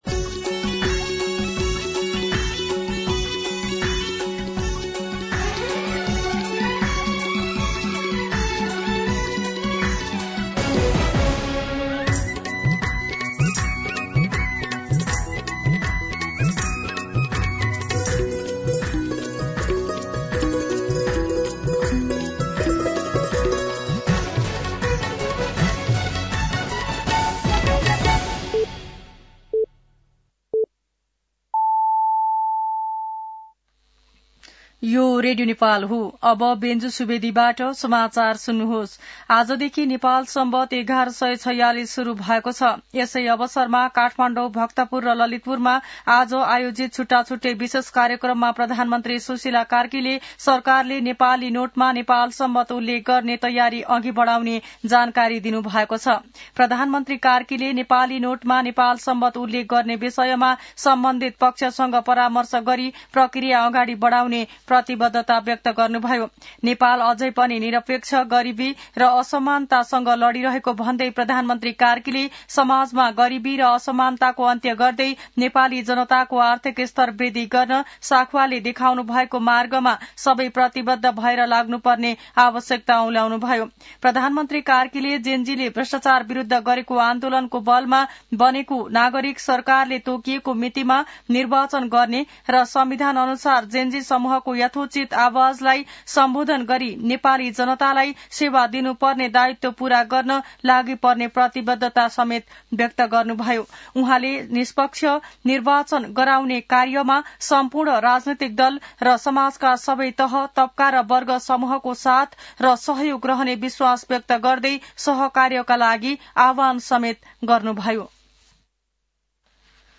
मध्यान्ह १२ बजेको नेपाली समाचार : ५ कार्तिक , २०८२